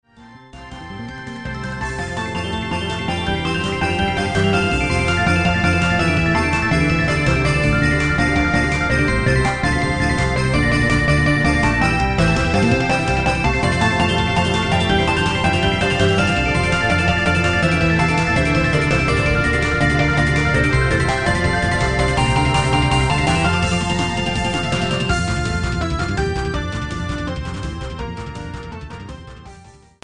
BPM=170
POINT 曲の初めと終わりに入っている、エルガーの『威風堂堂』！
曲分類コード [harukaze][or-01-001] オリジナル 明るい 速い フリー非公開 素材不可 ≪BACK 【最初の作品です。